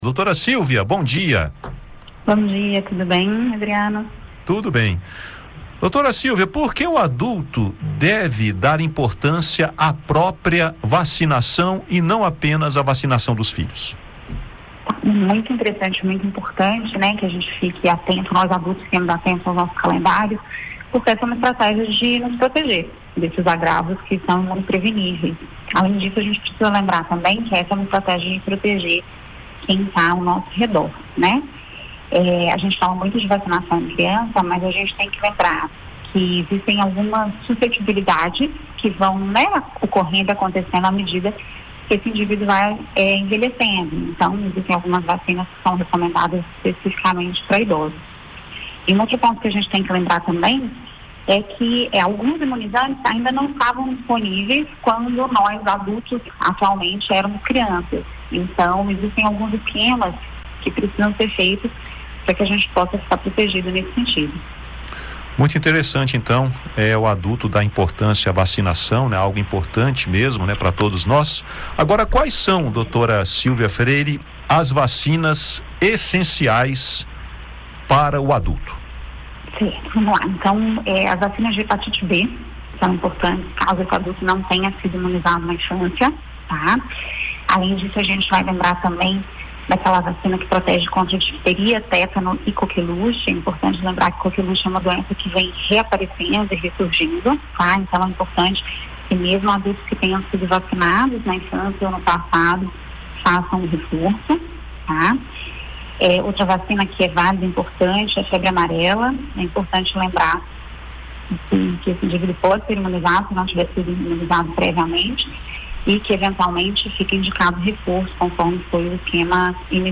*Entrevista originalmente veiculada em 13/12/2024.